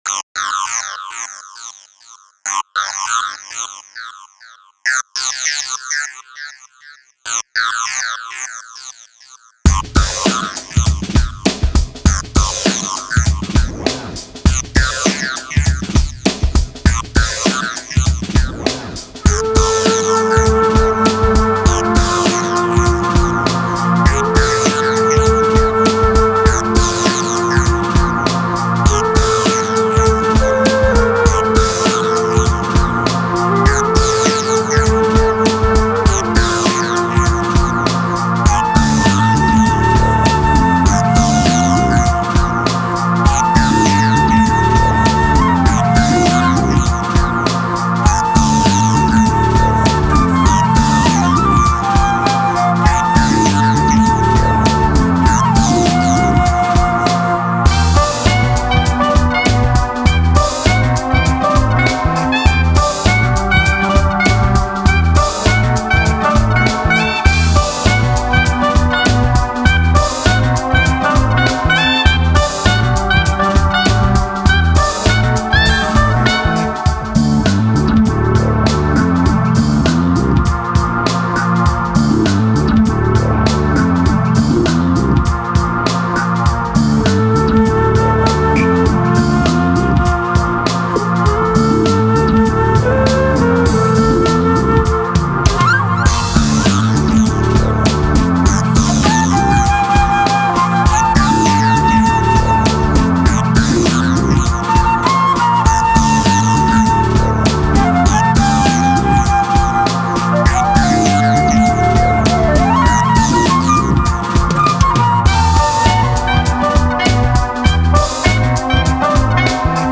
sax and flute
signature bass grooves